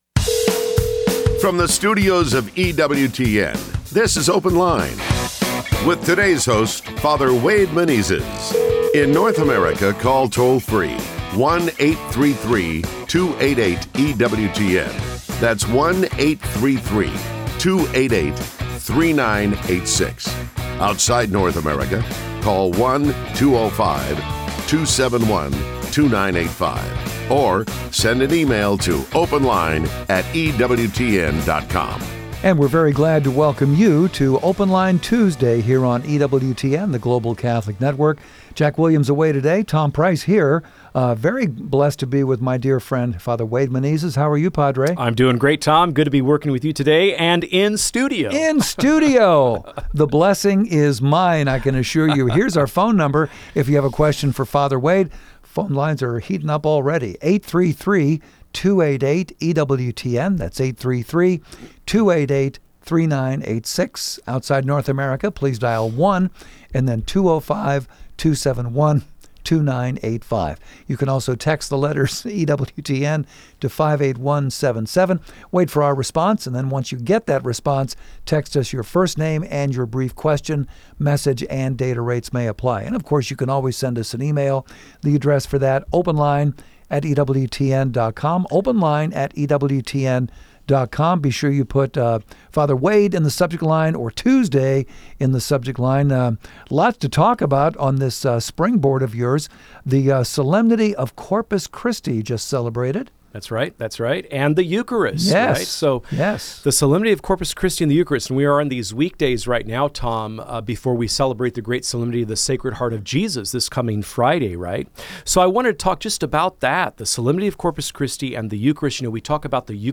Call in: 833-288-EWTN (3986) | Faith, Family, and Fellowship